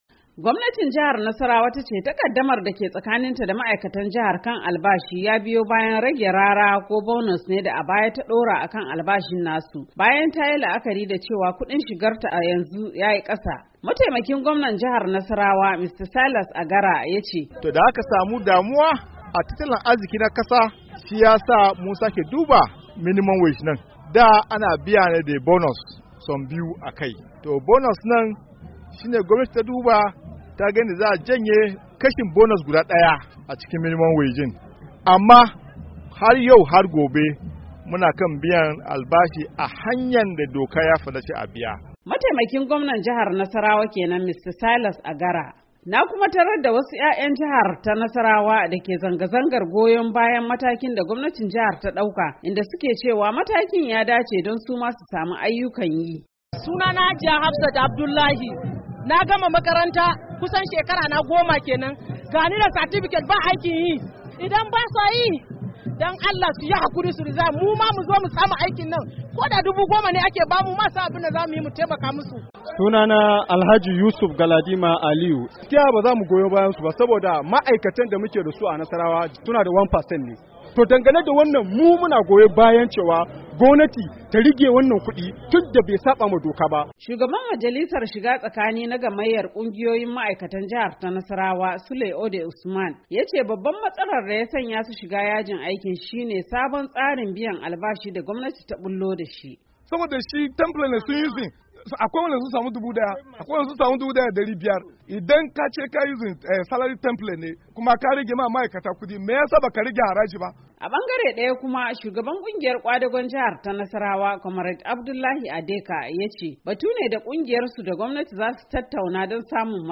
cikakken rahoto